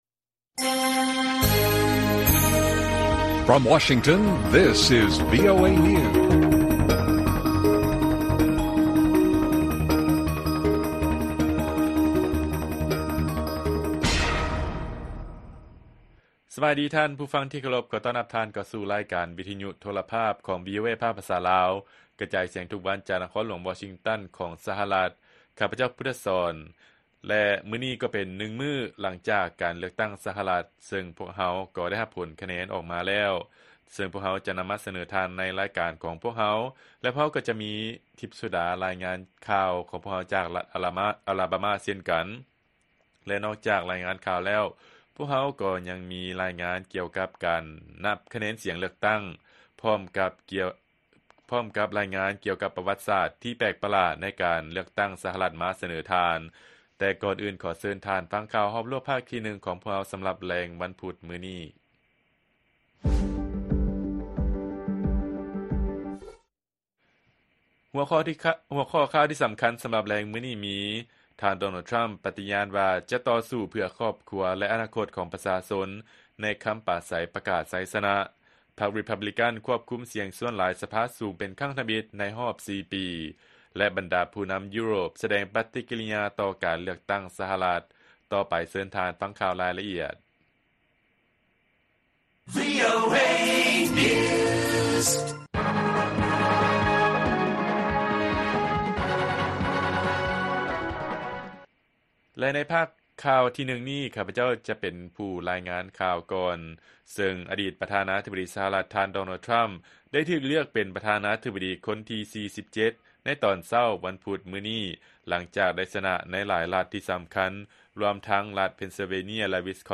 ລາຍການກະຈາຍສຽງຂອງວີໂອເອລາວ: ທ່ານ ດໍໂນລ ທຣຳ ປະຕິຍານວ່າຈະຕໍ່ສູ້ເພື່ອຄອບຄົວ ແລະ ອານາຄົດຂອງປະຊາຊົນ ໃນຄຳປາໄສປະກາດໄຊຊະນະ